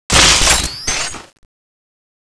jetgun_draw.wav